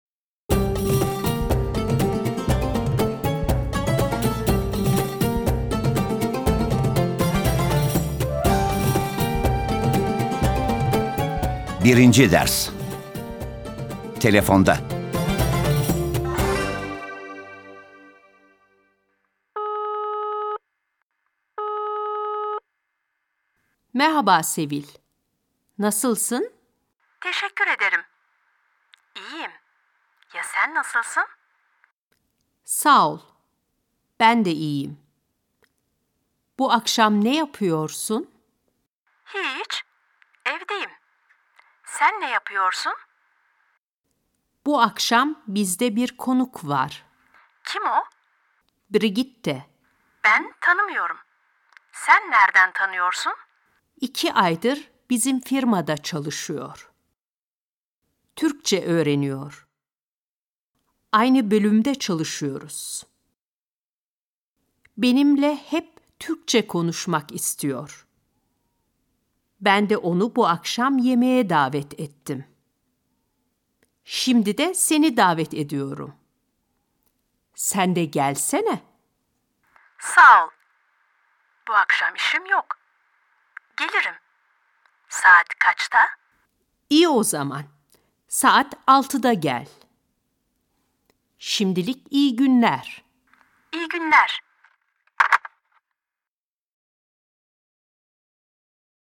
Vielfältige Übungen zum Hören und Sprechen